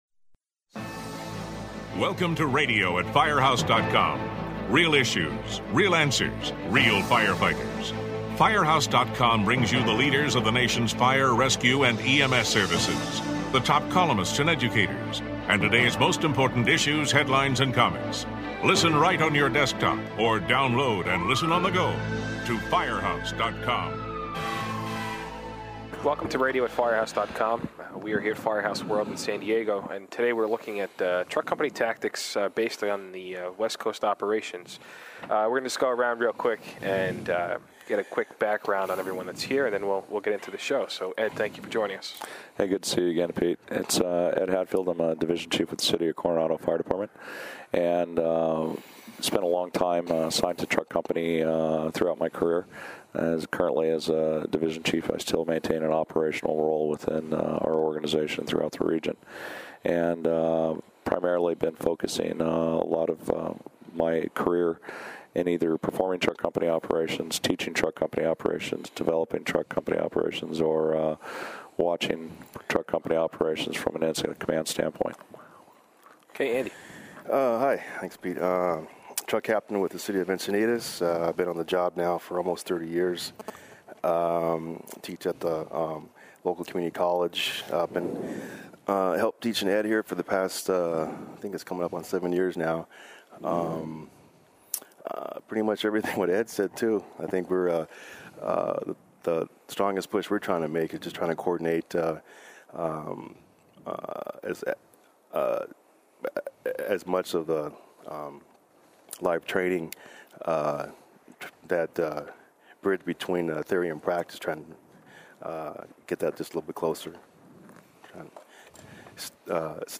The panelists discuss the main duties of the first-due truck company at structures fires and why it is important to complete all of the tasks. They weigh in on the importance of training on the basic and advanced skills and share what really makes a successful truck company, both on the street and in the station. Note: This podcast was recorded at Firehouse World in February.